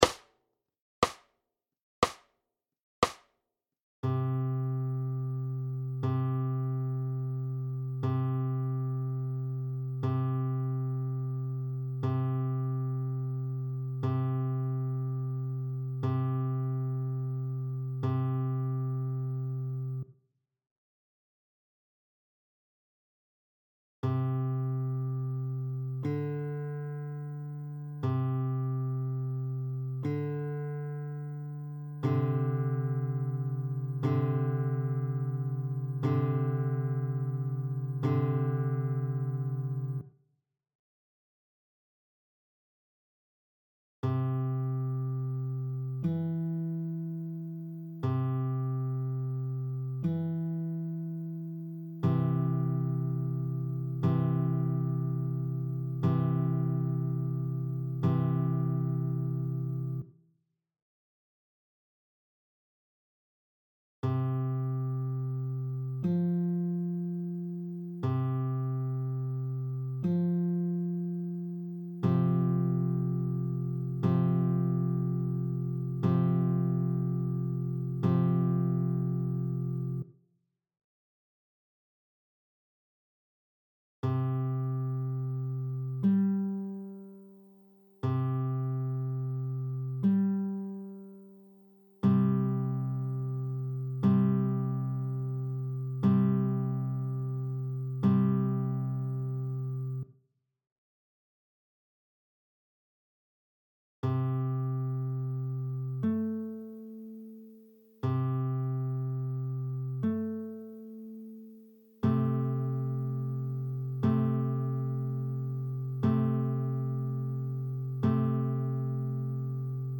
2.) Intervalle (gr./rein) der C-Dur-TL in I. Lage, vertikal, aufwärts: PDF